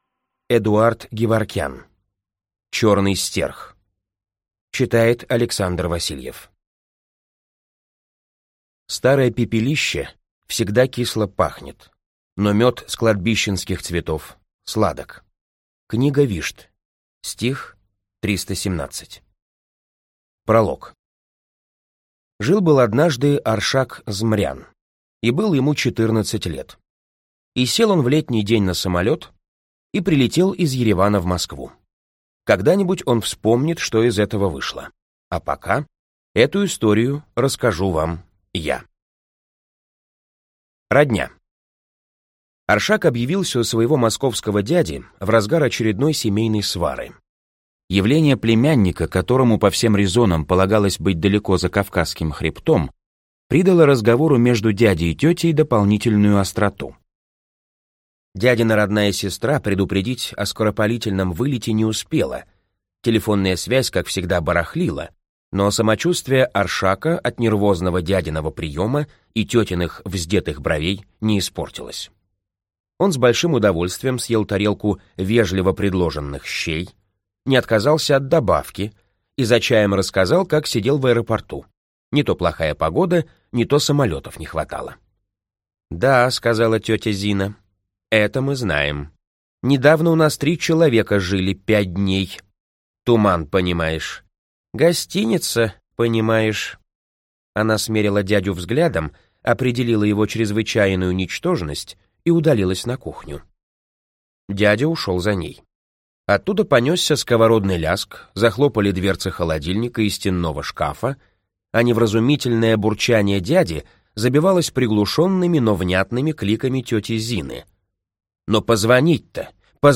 Аудиокнига Черный Стерх | Библиотека аудиокниг
Прослушать и бесплатно скачать фрагмент аудиокниги